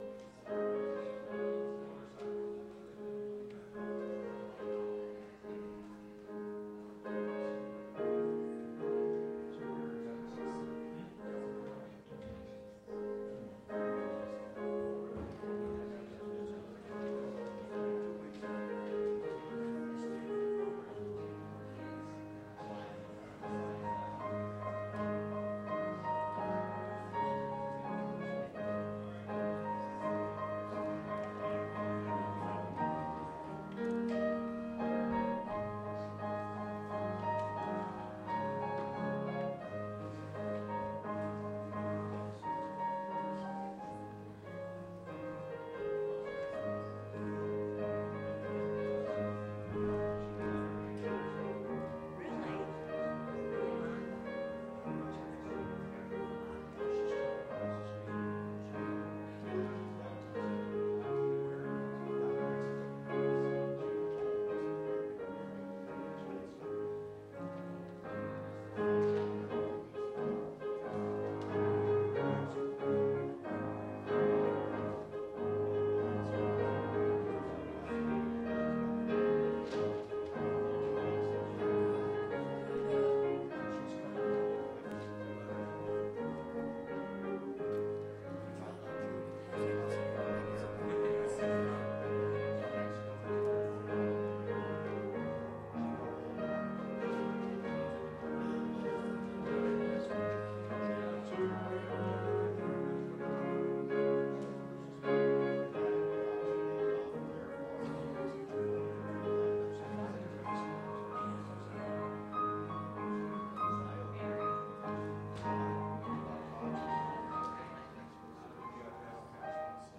Calvin Christian Reformed Church Sermons